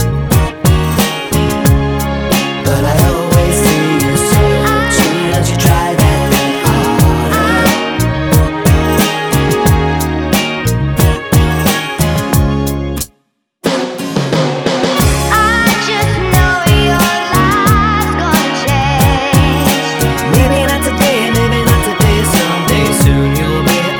Minus Female Vocal Pop (2000s) 3:33 Buy £1.50